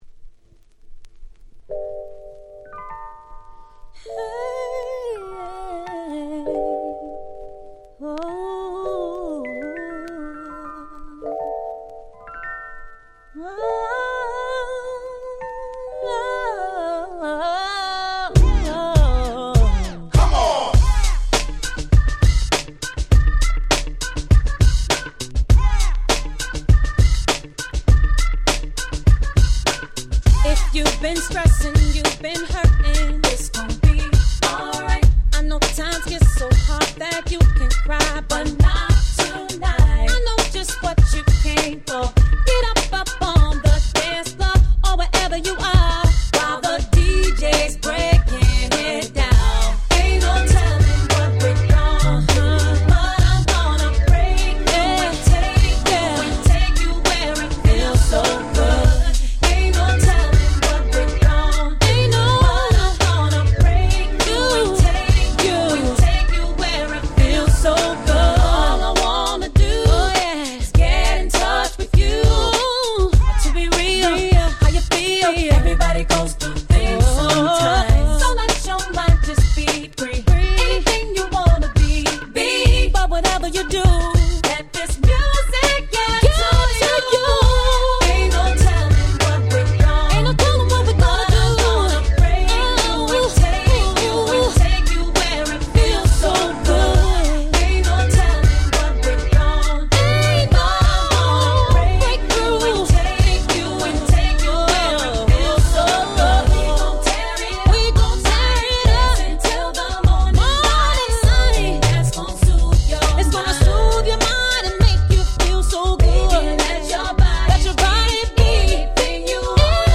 05' Super Nice R&B !!